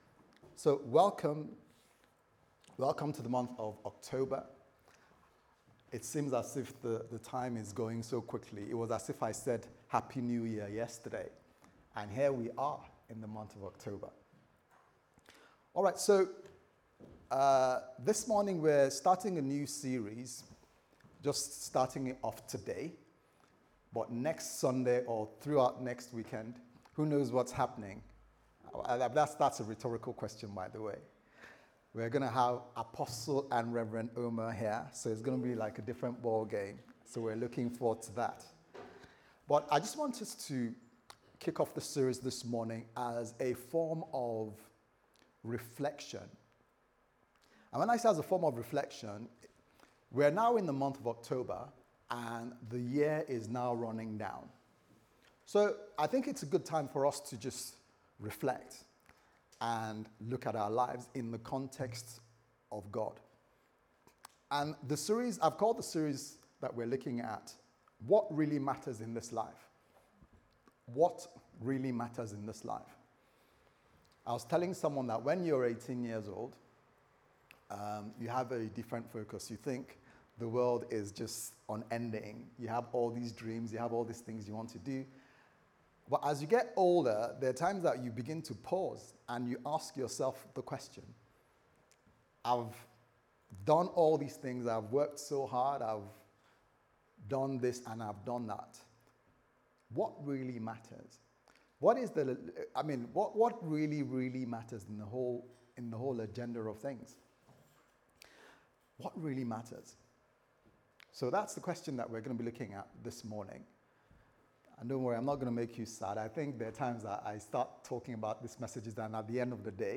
What Really Matters Service Type: Sunday Service Sermon « Living A Fulfilled Life